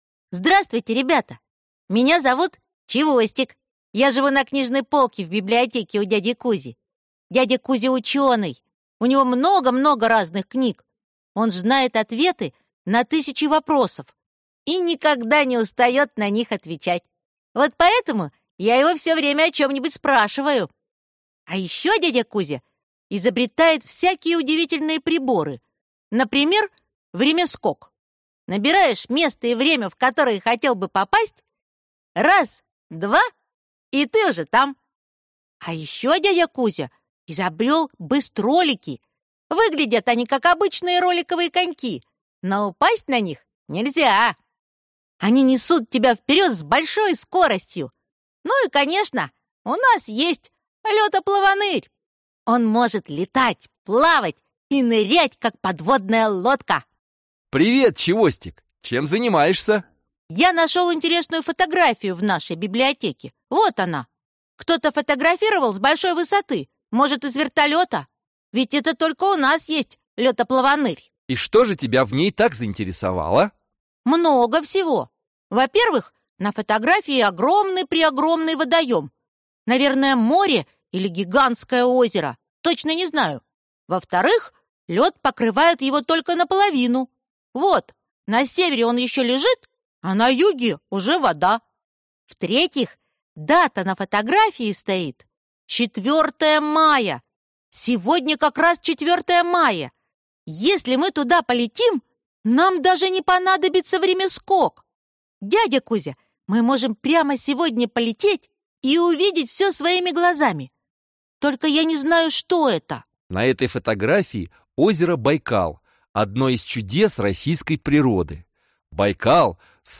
Аудиокнига Байкал | Библиотека аудиокниг